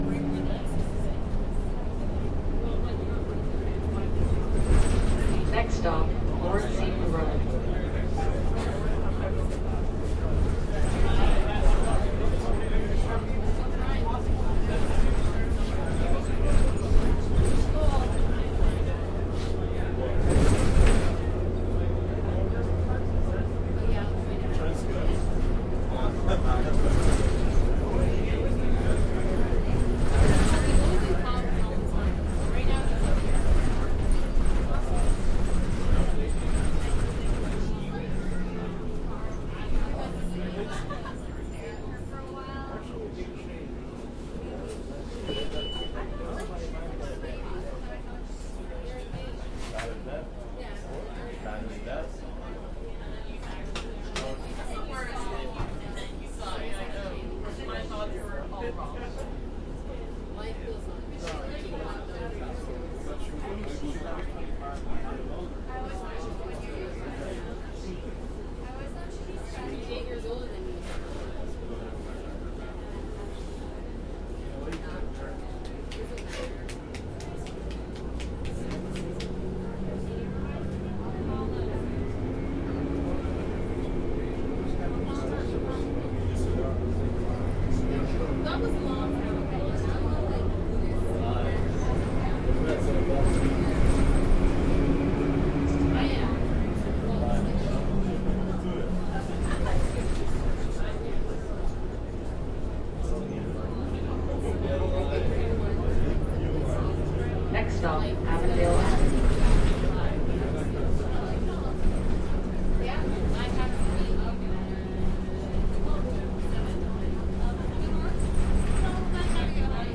公交车环境
描述：周五晚上在多伦多乘坐深夜的巴士。 人们在交谈，1个公交车站的信号噪音，2个公交车站的广播，公交车从内部运行的声音
Tag: 环境 公交车站 公交车 人群中 现场记录 电车 多伦多